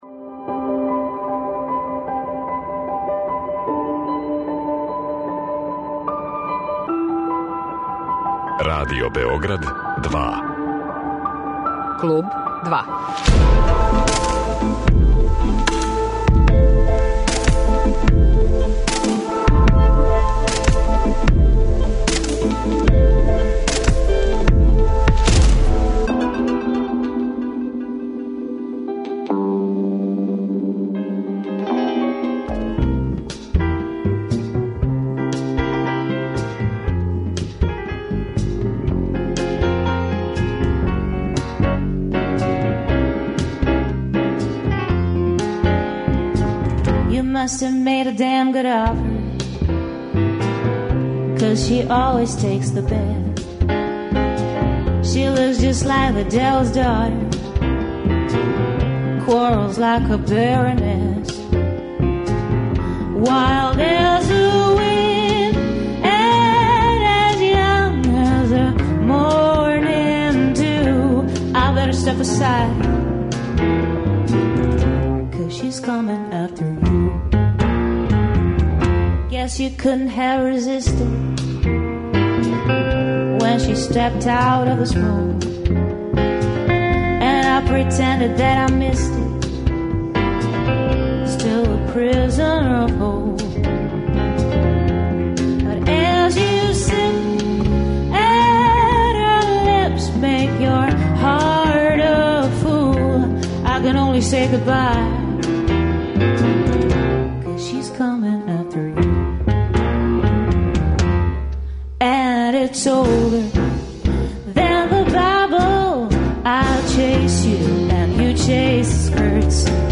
Овог петка, угостићемо изузетну блуз, џез кантауторку.